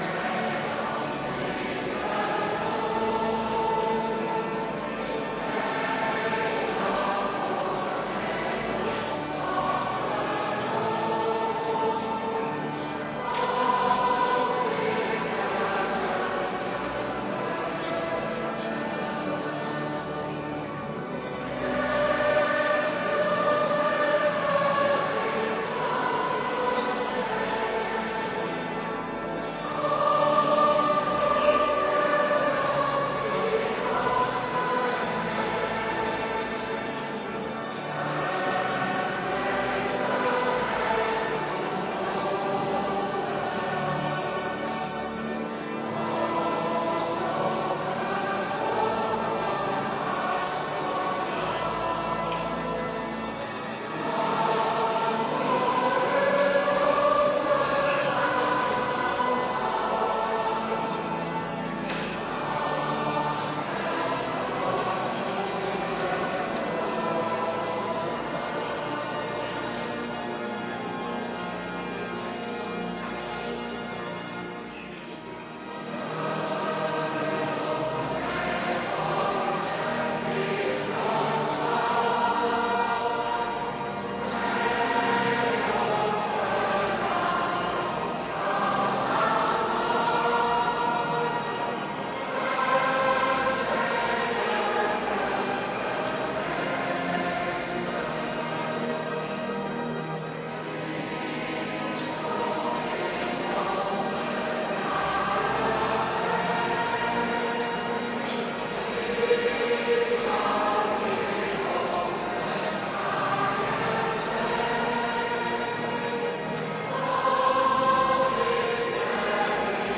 Kerkdiensten
Adventkerk Zondag week 49